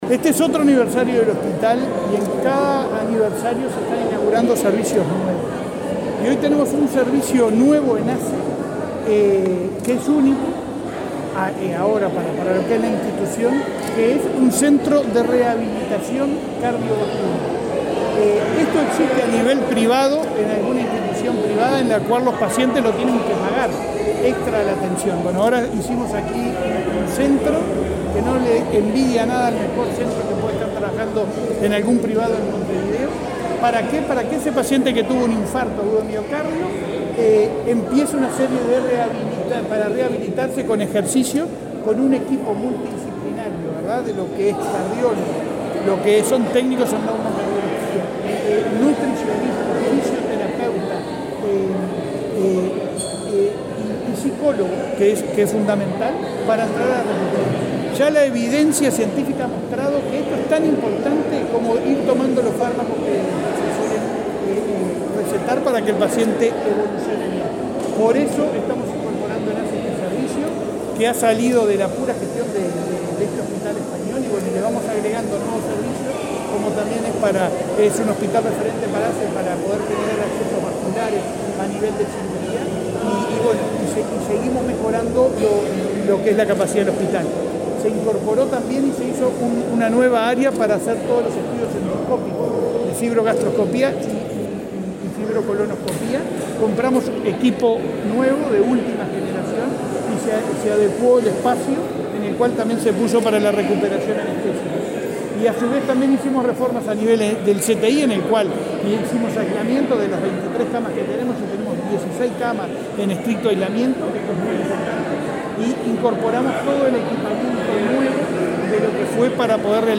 Declaraciones del presidente de ASSE, Leonardo Cipriani
En la oportunidad, también se inauguró el área de rehabilitación cardiovascular de la institución. Luego, Cipriani dialogó con la prensa.